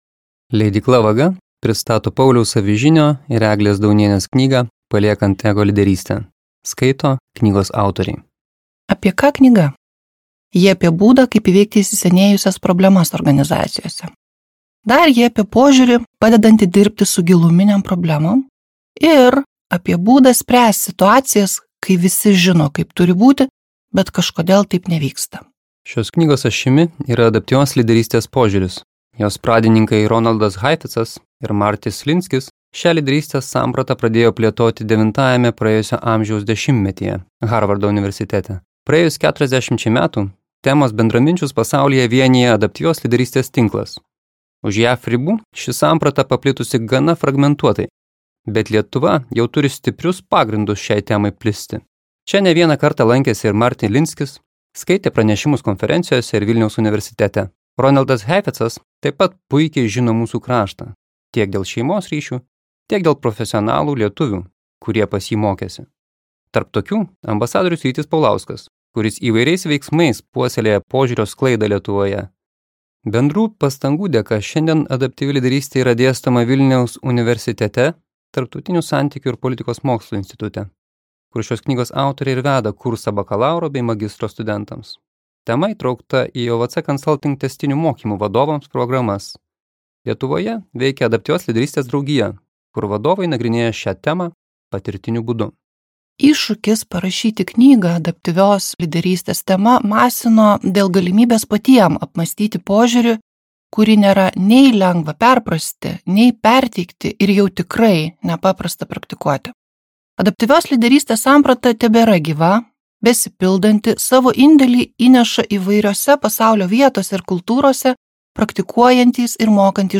Paliekant Ego lyderystę | Audioknygos | baltos lankos